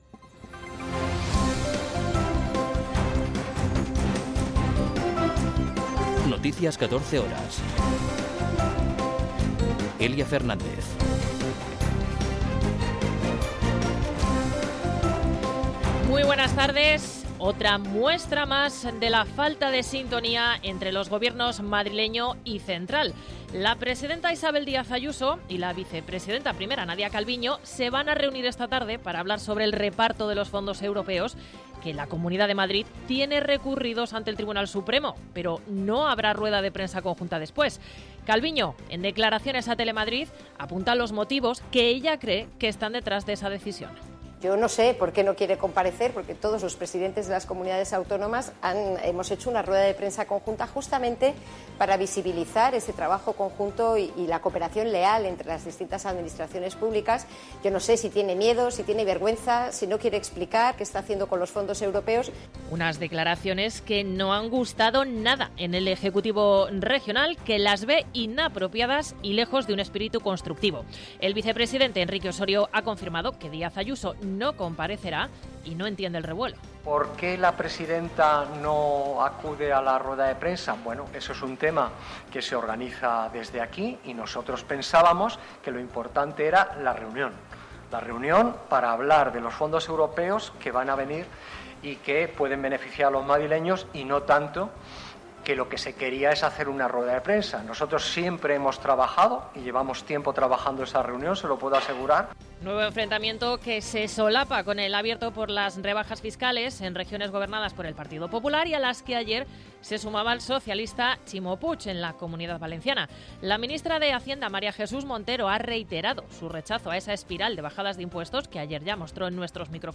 Noticias 14 horas 28.09.2022